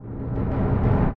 Suicide Drums Sound Effect
suicide-drums.mp3